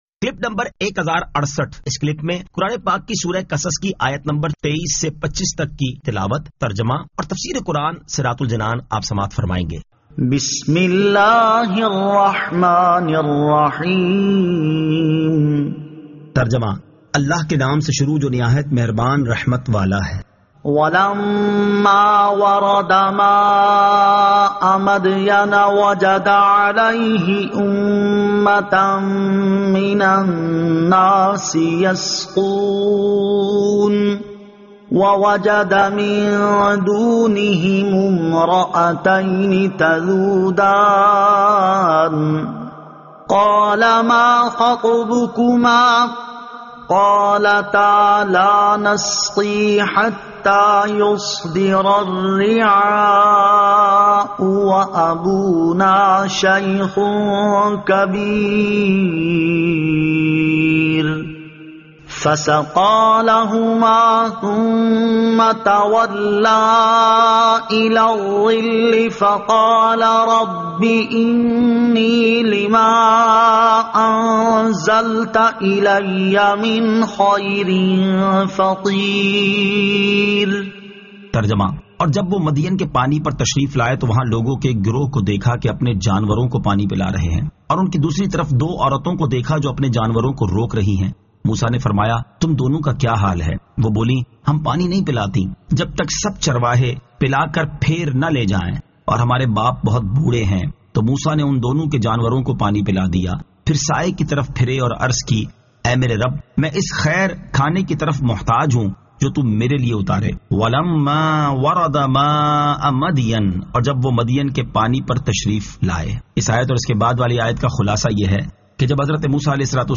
Surah Al-Qasas 23 To 25 Tilawat , Tarjama , Tafseer